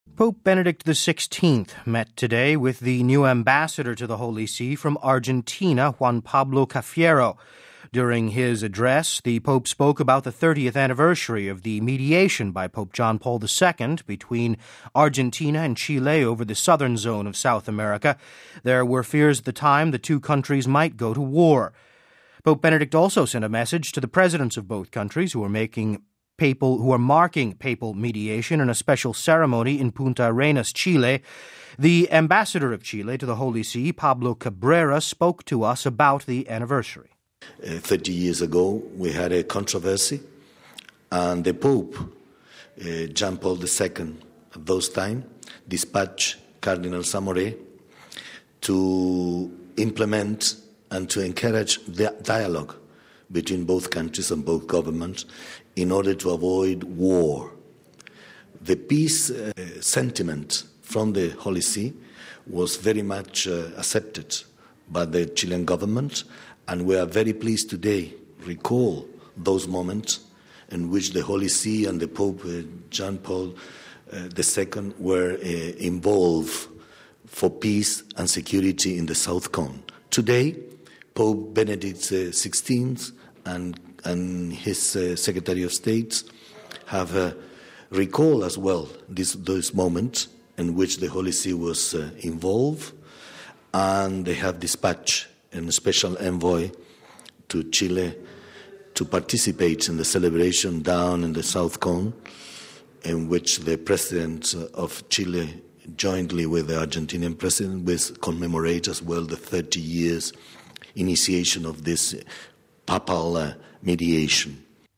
The Ambassador of Chile to the Holy See, Pablo Cabrera, spoke to us about the anniversary...